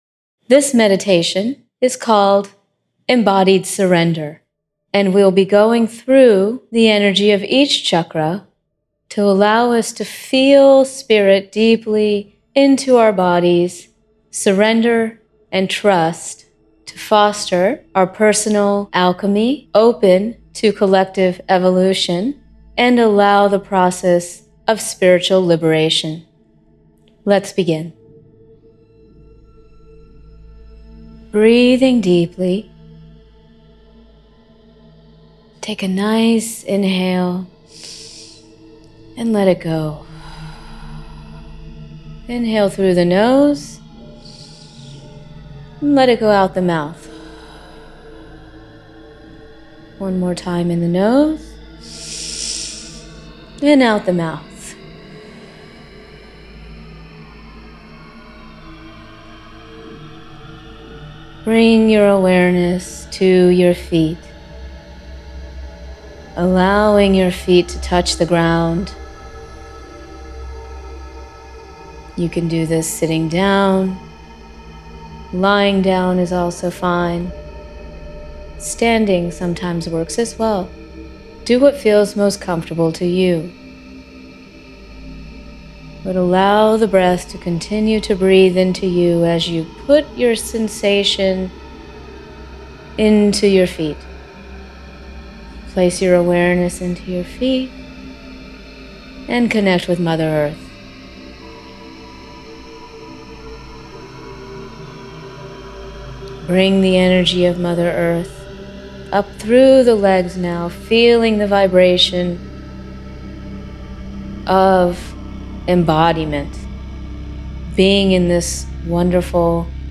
Guided Meditation 1